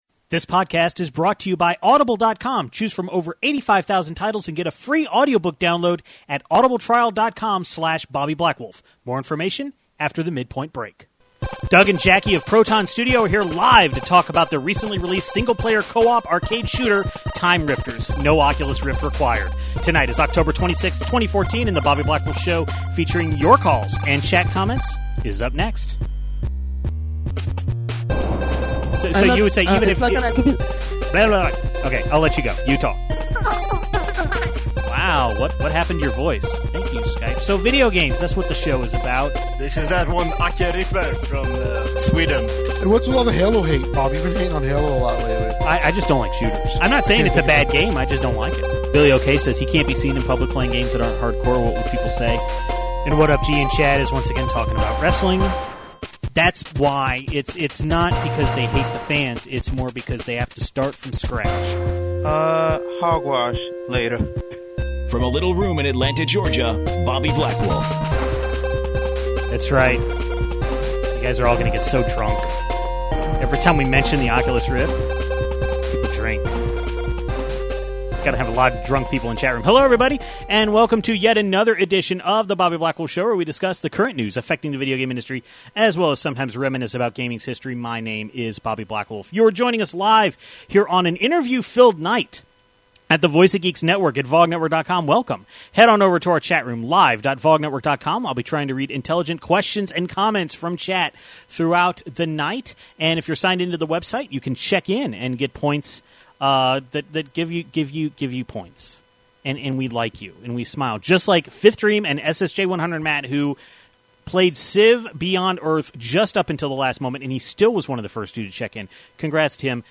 We gave our first impressions of Civilization: Beyond Earth and Disney Fantasia: Music Evolved. Then we take calls about Bayonetta 2, Shantae and the Pirate's Curse, and the Final Fantasy XIV Fanfest.